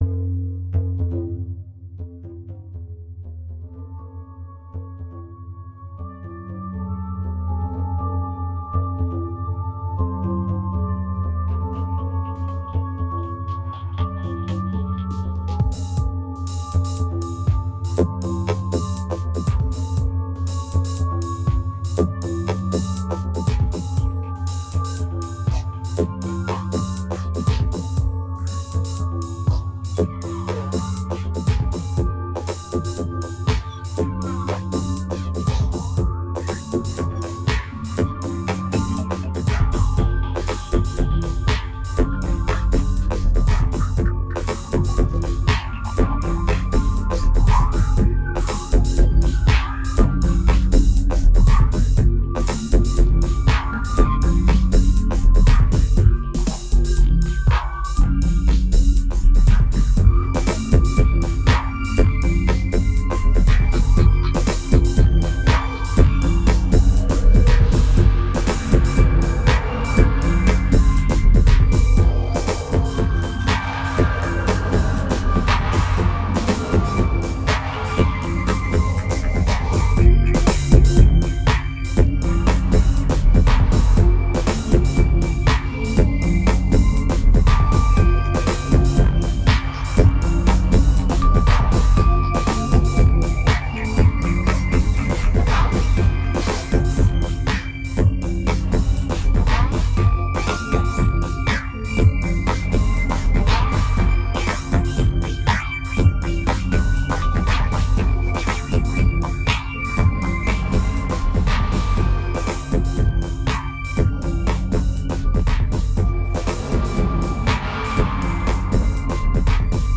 2006📈 - -79%🤔 - 120BPM🔊 - 2009-05-28📅 - -476🌟